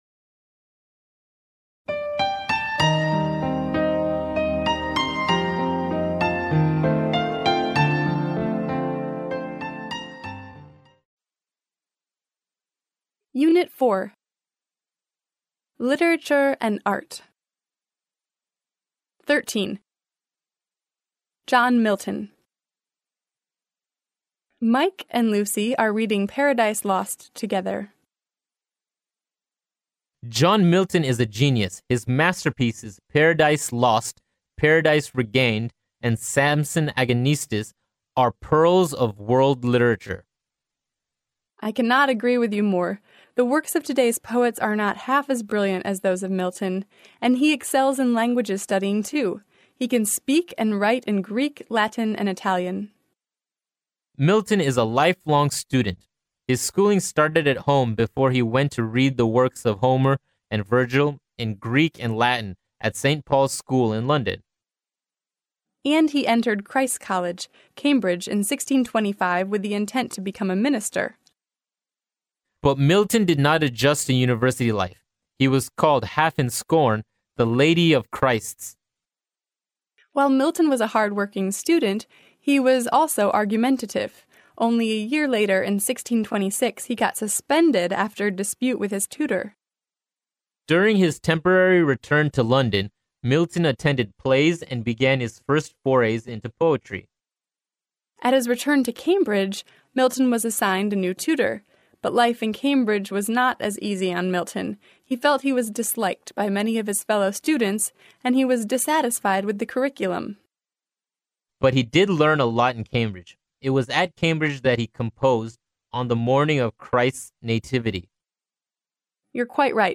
剑桥大学校园英语情景对话13：失明诗人弥尔顿（mp3+中英）